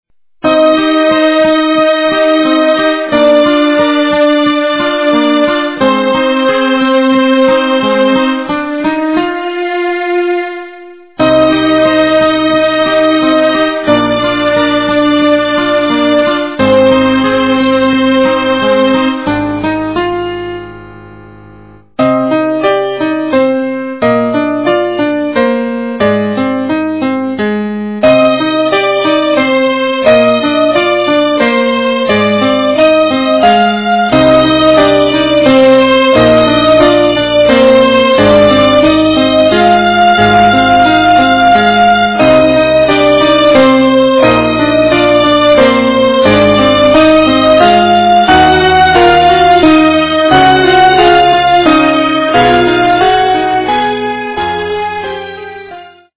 - фильмы, мультфильмы и телепередачи
полифоническую мелодию